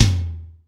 ROOM TOM3A.wav